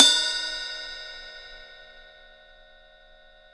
J   CRASH 2.wav